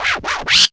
SCREETCH.wav